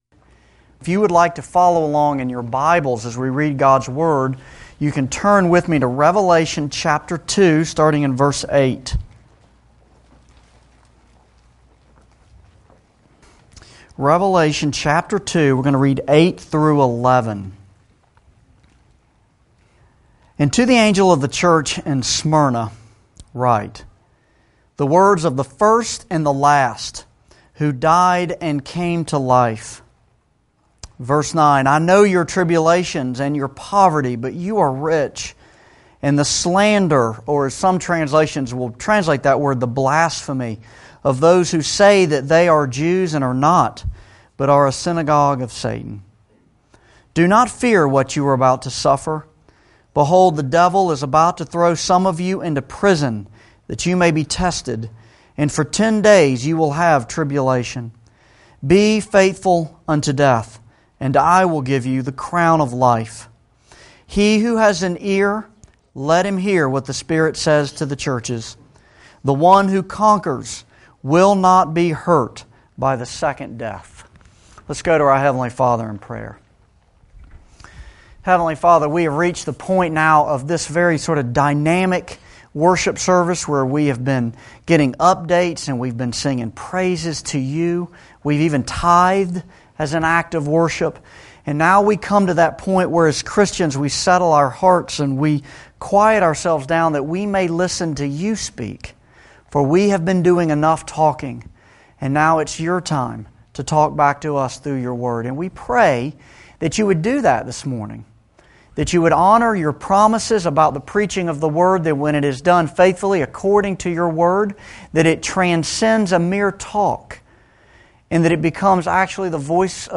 Revelation-Sermon-7.mp3